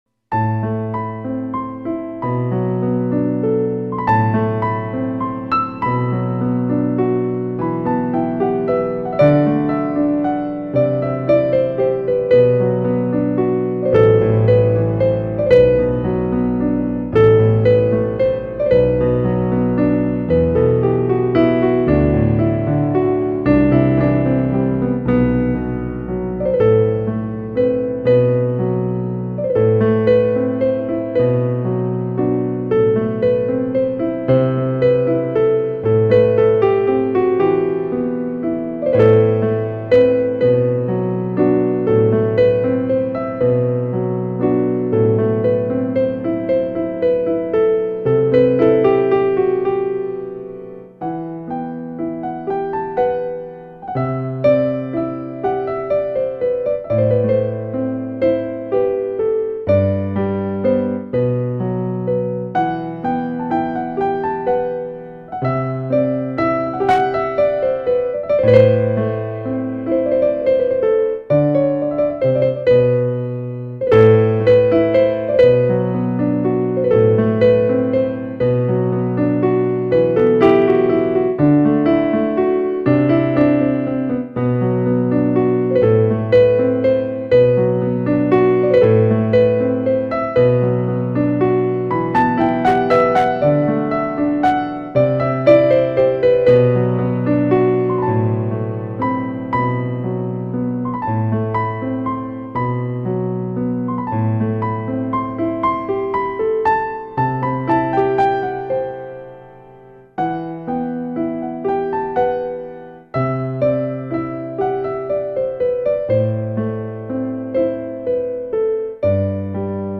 (Enstrümantal)
Diğer eserlere göre daha içten ve nostaljik bir hüzün taşır.